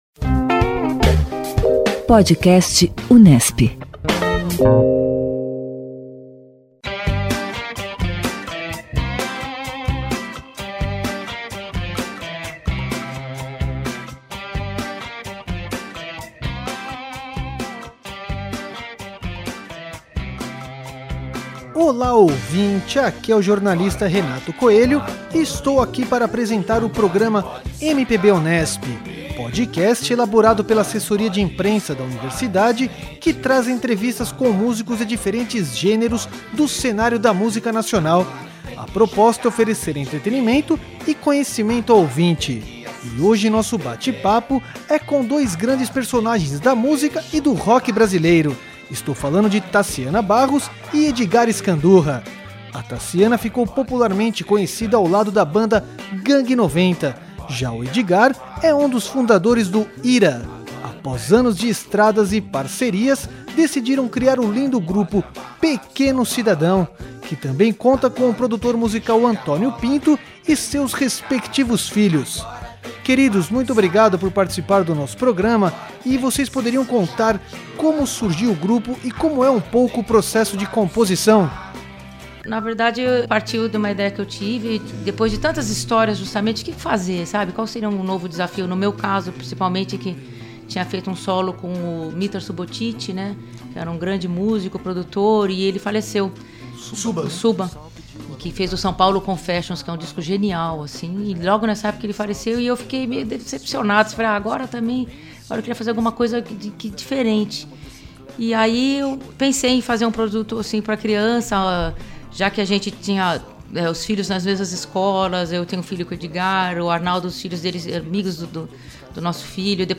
Este Podcast Unesp conta com a faixa "Mamãe Tamo Chegando?", faixa do disco "Pequeno Cidadão 2".